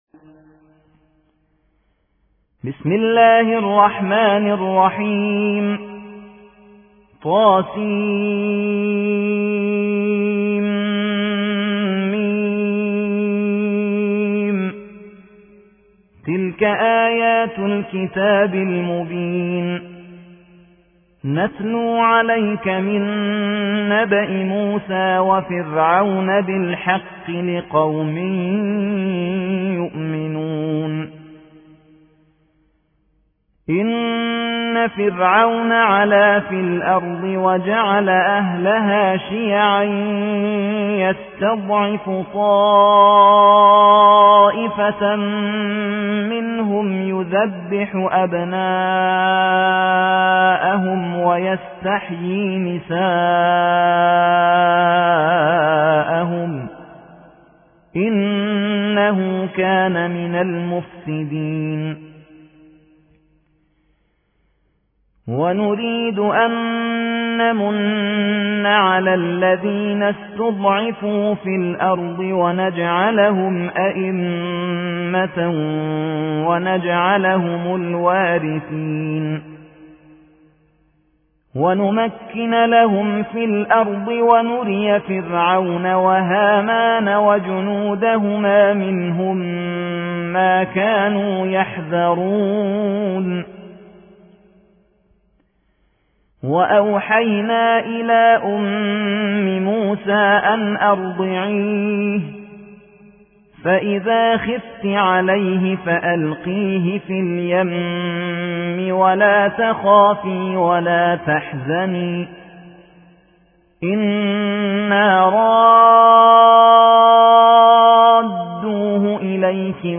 28. سورة القصص / القارئ